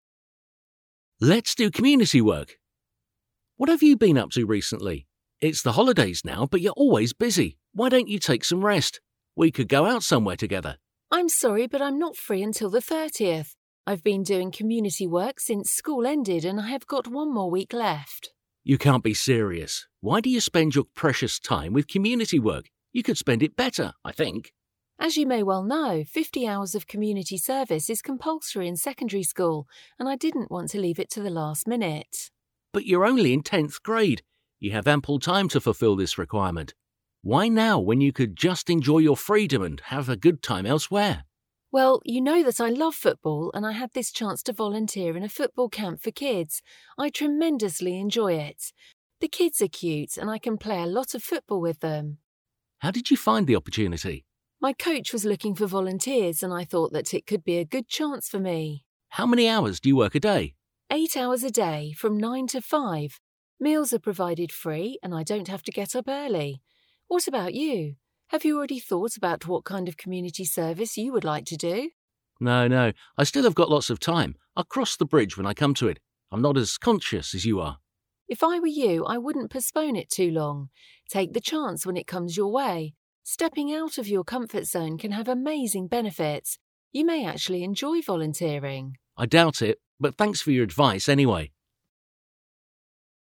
A magazin 80. oldalán található párbeszédet hallgathatod meg itt.